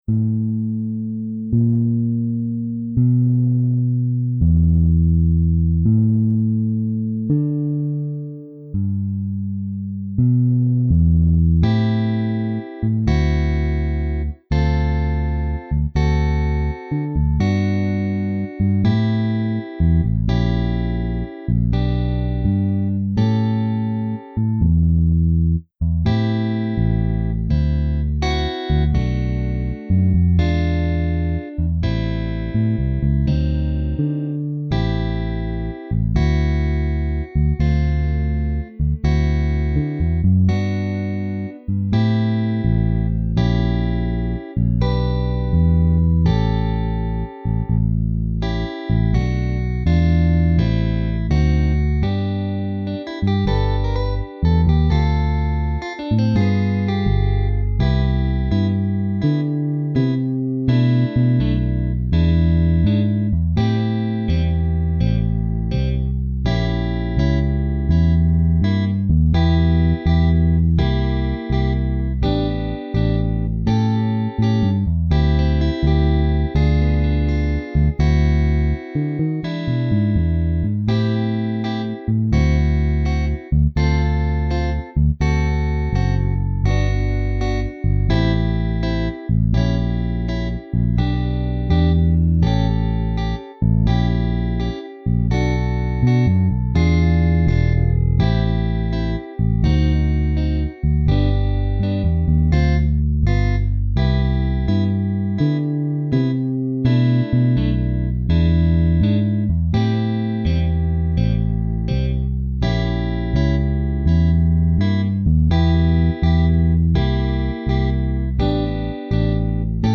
Naked     (after 8 bass notes)